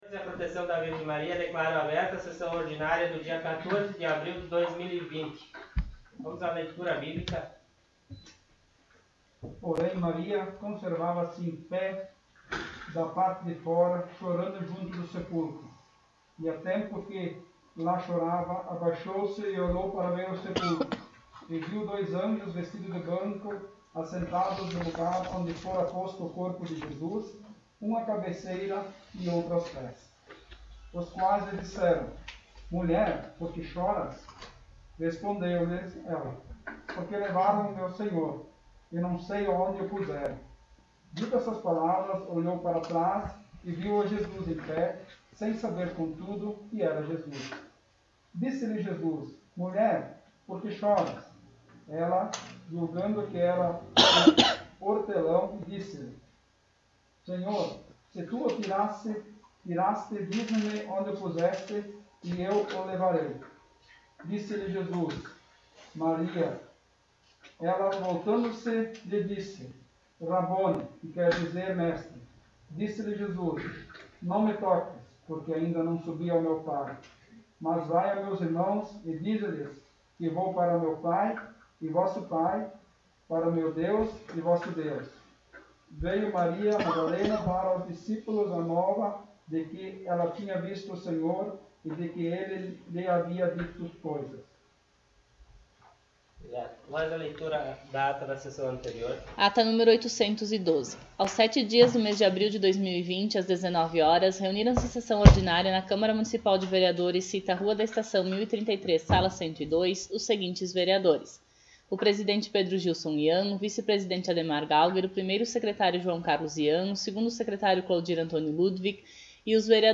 Sessão Ordinária do dia 14 de abril de 2020